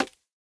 Minecraft Version Minecraft Version snapshot Latest Release | Latest Snapshot snapshot / assets / minecraft / sounds / block / bamboo / place6.ogg Compare With Compare With Latest Release | Latest Snapshot